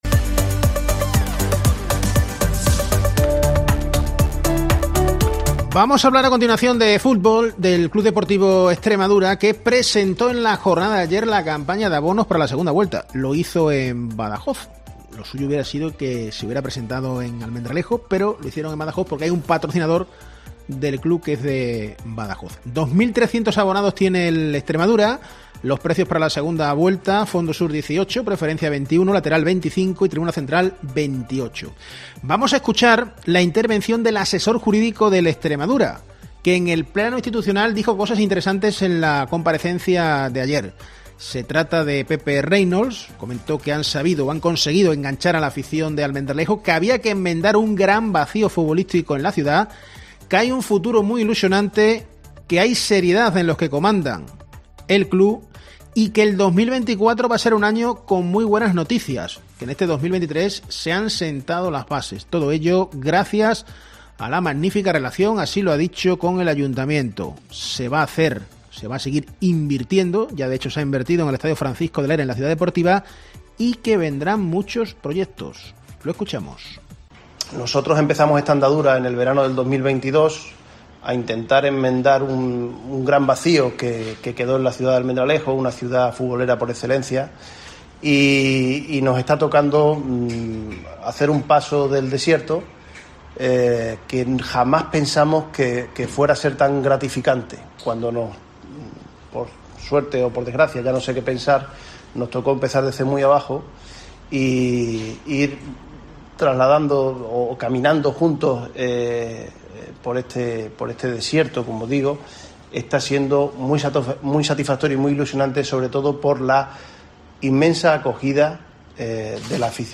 El CD Extremadura ha presentado su campaña de abonos para la segunda vuelta del campeonato, y no lo ha hecho en Almendralejo, sino en Badajoz, en la sede de uno de sus patrocinadores.